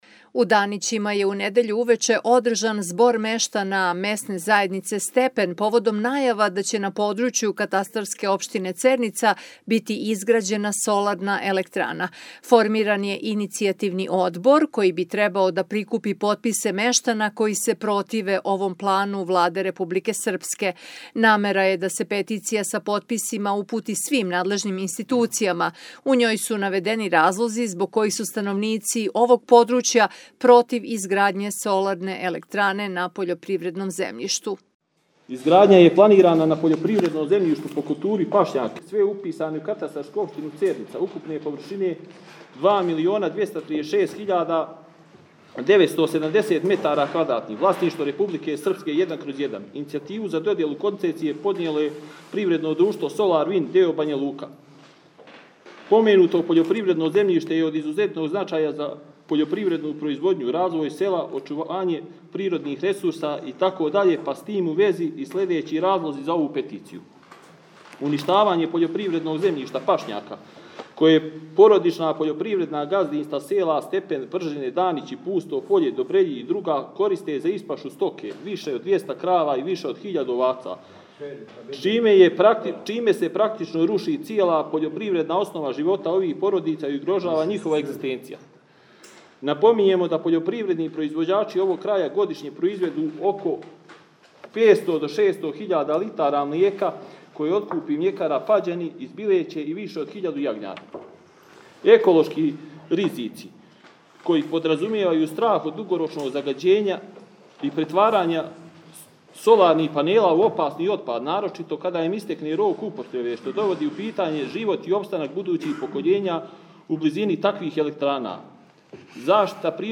U Danićima je, u ponedjeljak uveče, održan zbor meštana Mesne zajednice Stepen povodom najava da će na području katastarske opštine Cernica biti izgrađena solarna elektrana.
zbor-mestana-stepena-protiv-solara.mp3